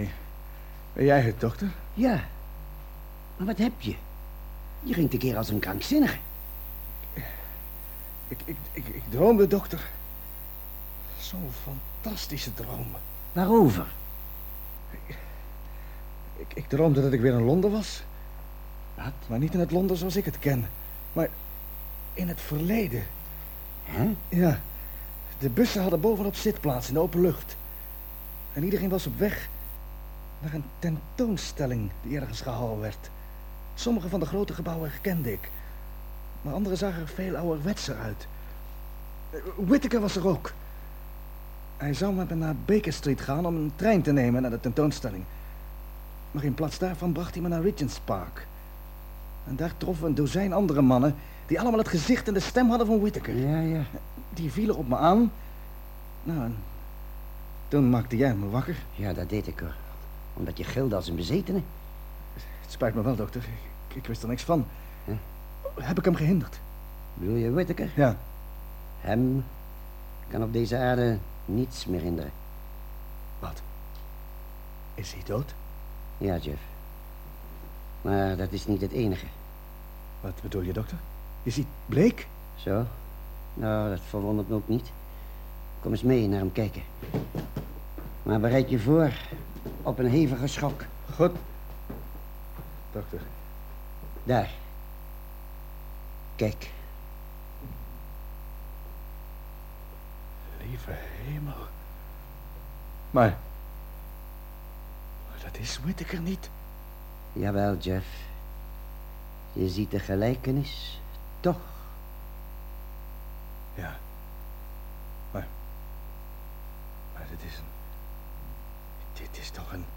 In de Nederlandse versie stelt Jeff aan de dokter dezelfde vraag (als je heel goed luistert), maar door zijn intonatie lijkt het net of het Whitaker is die bleek zou zien.